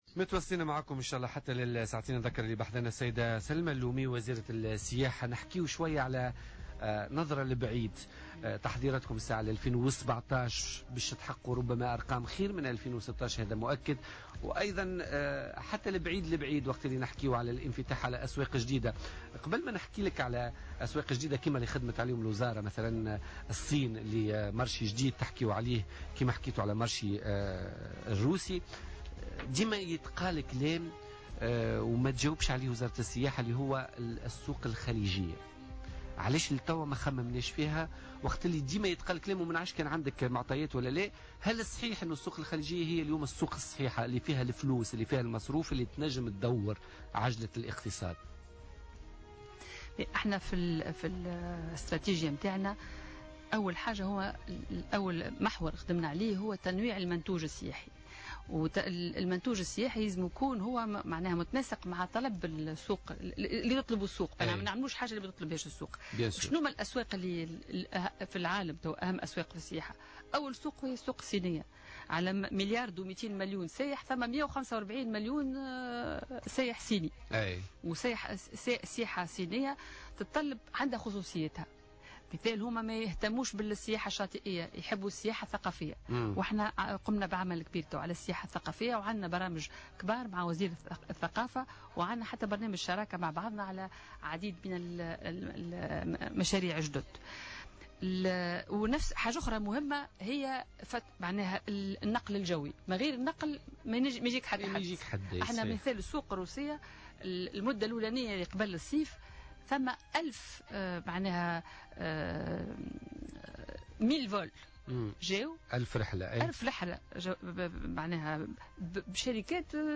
قالت وزيرة السياحة والصناعات التقليدية سلمى اللومي الرقيق خلال استضافتها اليوم في برنامج "بوليتكا" إن السوق الصينية تعد أهم سوق خارجية بالنسبة للسياحة التونسية.